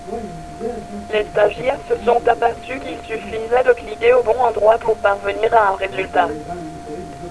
L'ordinateur vous parle encore